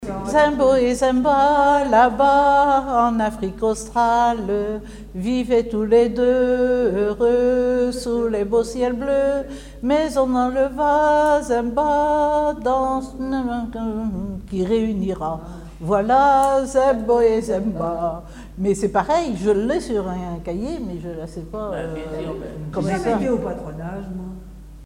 Chansons et formulettes enfantines
Pièce musicale inédite